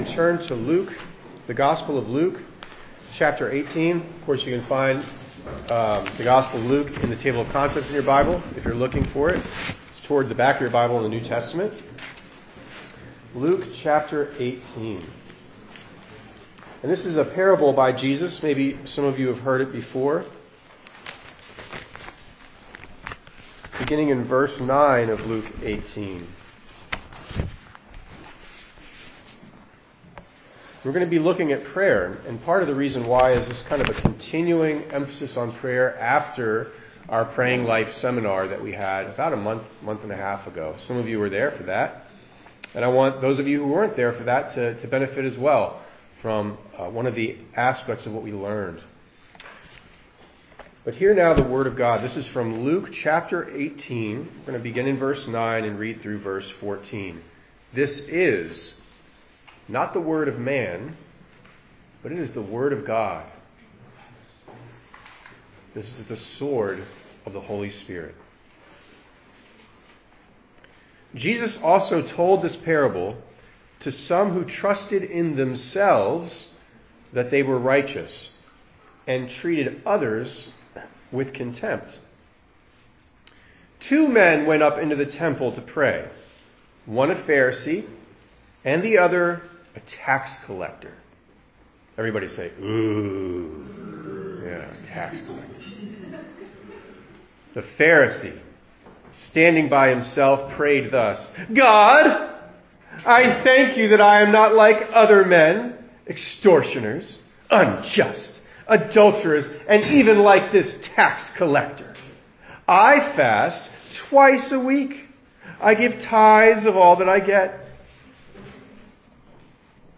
6_1_25_ENG_Sermon.mp3